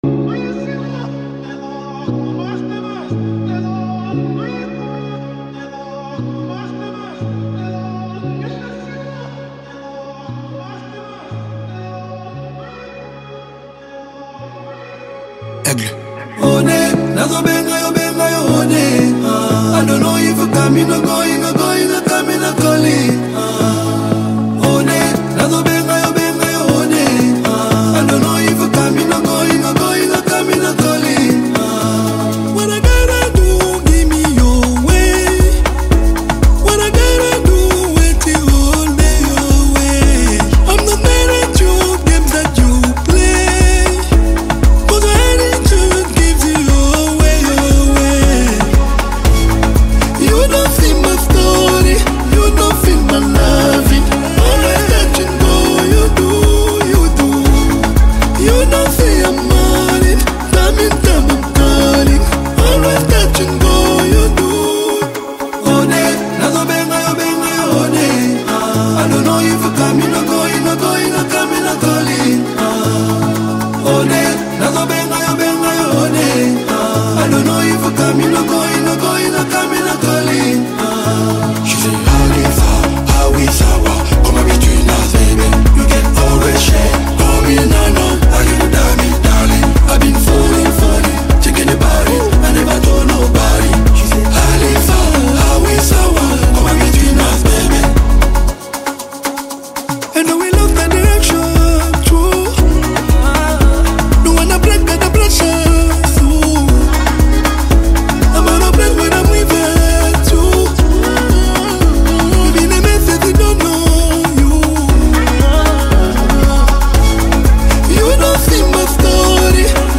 vibrant new track